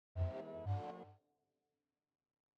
teleport_goto_lessfx.wav